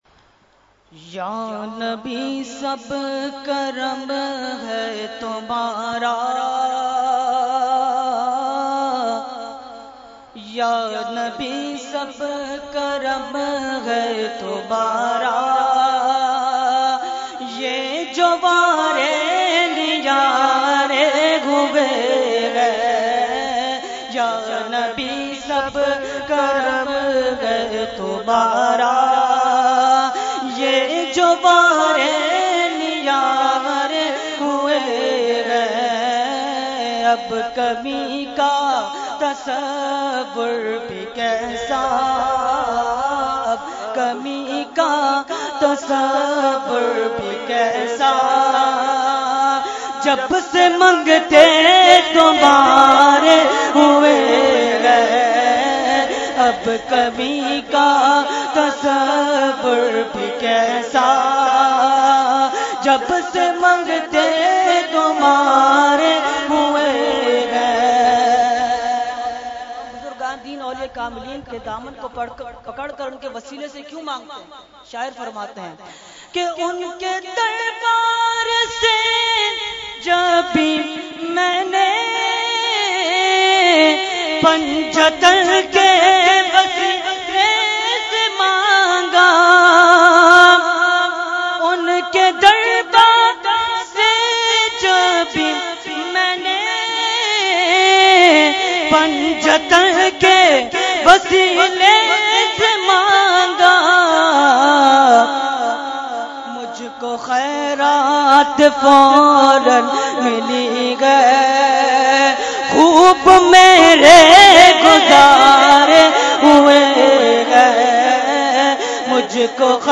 Category : Naat | Language : UrduEvent : Urs Ashraful Mashaikh 2017